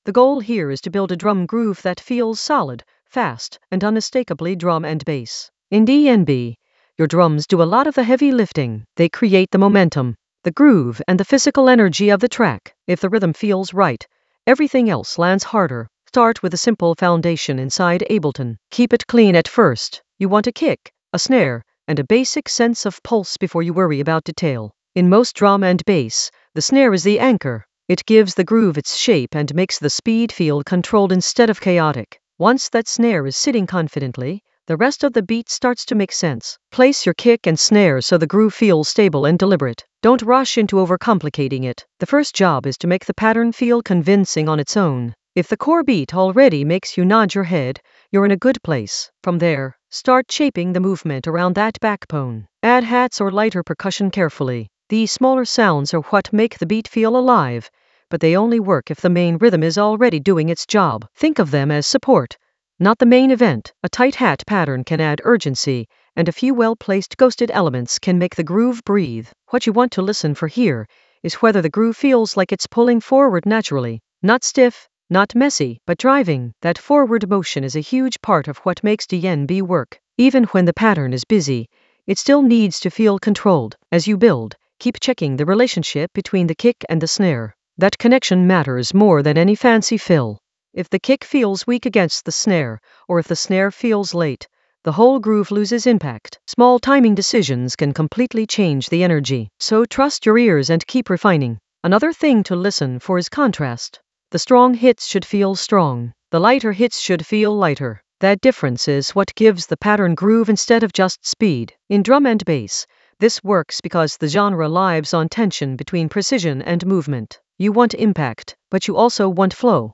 An AI-generated beginner Ableton lesson focused on Ramos FX in the FX area of drum and bass production.
Narrated lesson audio
The voice track includes the tutorial plus extra teacher commentary.